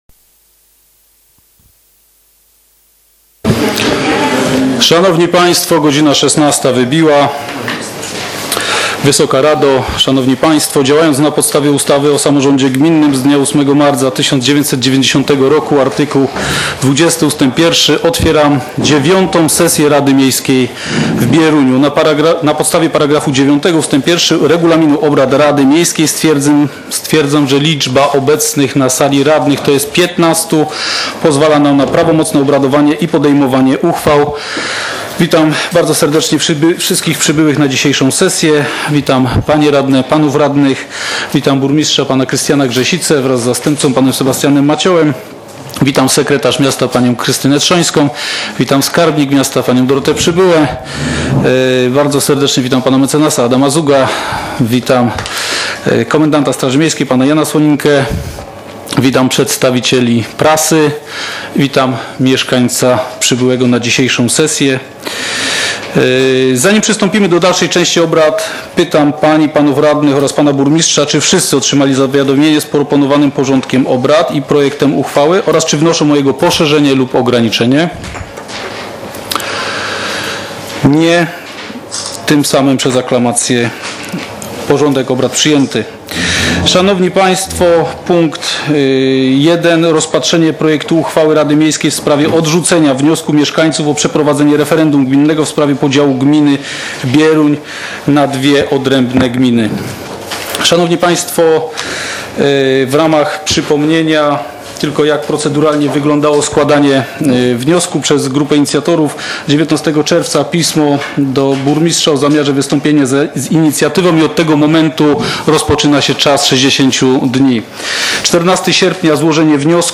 z obrad IX sesji Rady Miejskiej w Bieruniu, która odbyła się w dniu 11.09.2015 r. w dużej sali szkoleń Urzędu Miejskiego w Bieruniu